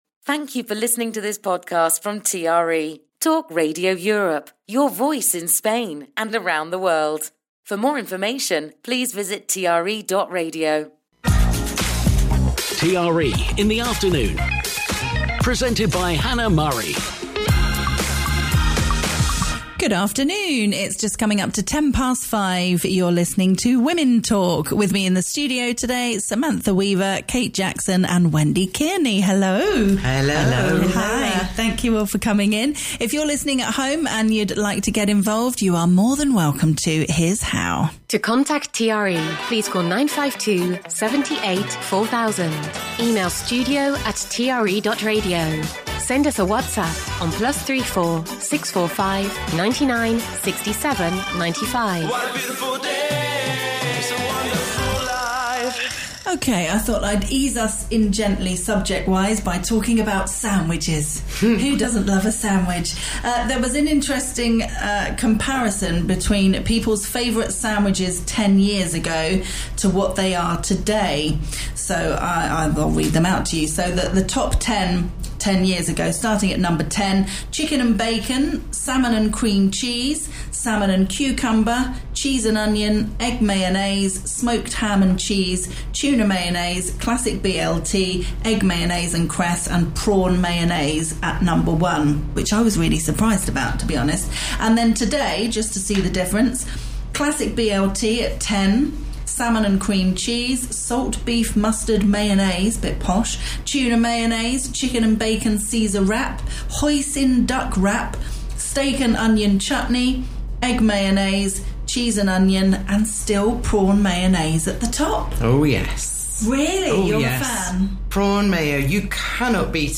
her panel of guests